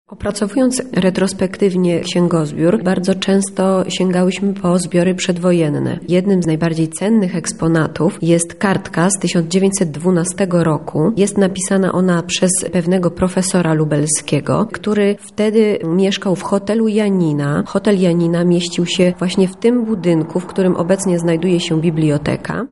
Książkowe znaleziska dają nam obraz ówczesnych czytelników, mówi